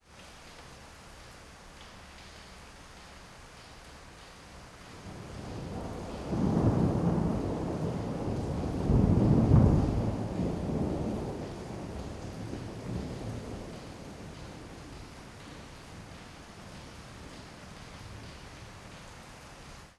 rain_ambiX.wav